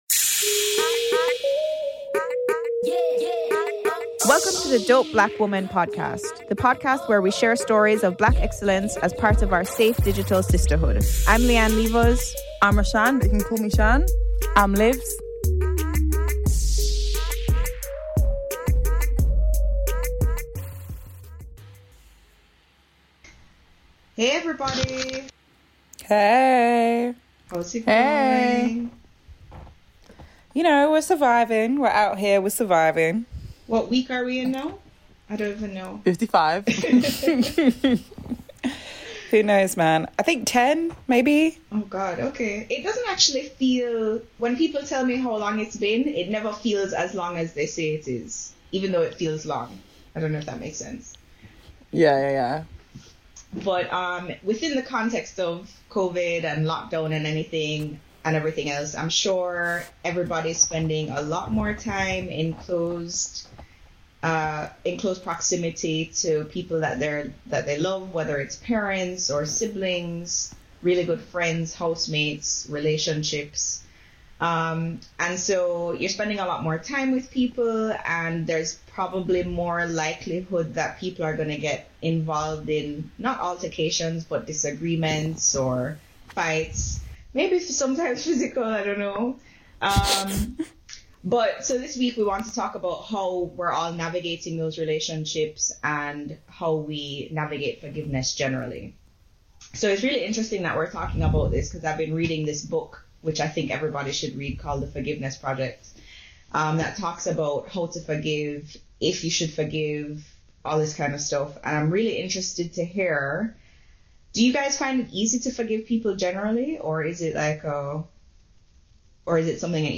This week the Dope Black Women discuss what forgiveness means to them, how they deal with making apologies and how letting go of the past brings them peace in the present.